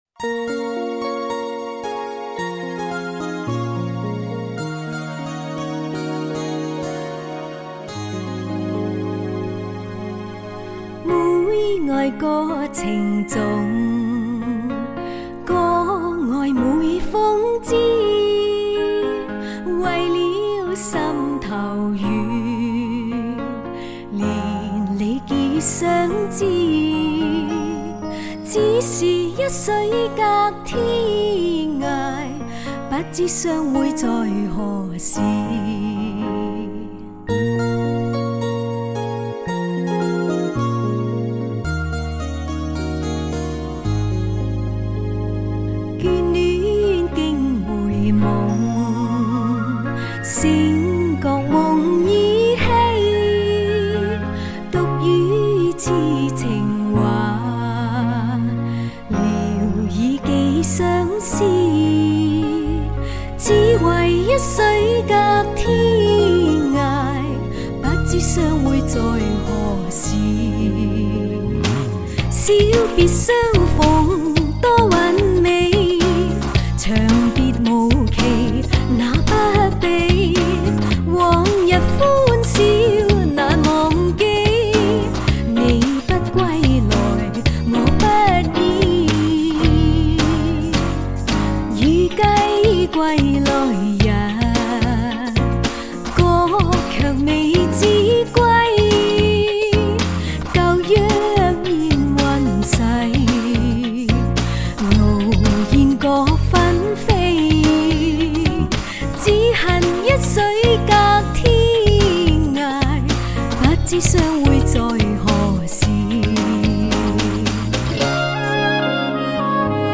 她的歌声温柔甜美，婉转悦耳，清新雅致，犹如一阵阵春风吹入人们的心田。